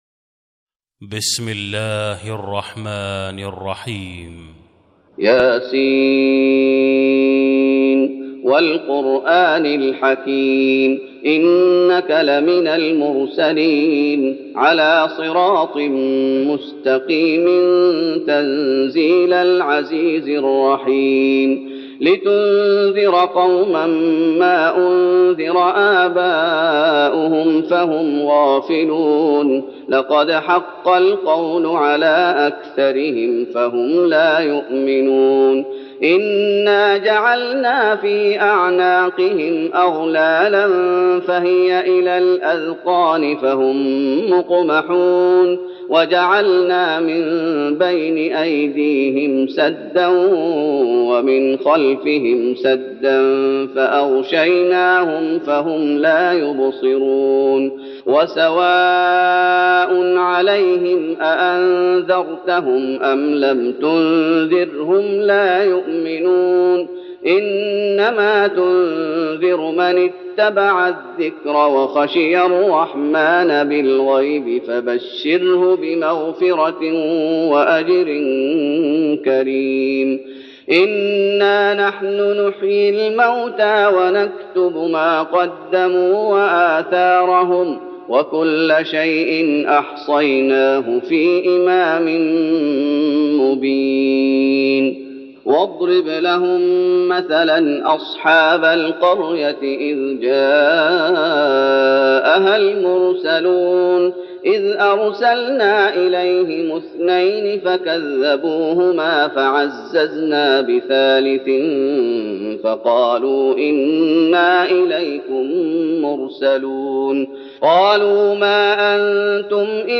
تراويح رمضان 1412هـ من سور يس (1-19) Taraweeh Ramadan 1412H from Surah Yaseen > تراويح الشيخ محمد أيوب بالنبوي 1412 🕌 > التراويح - تلاوات الحرمين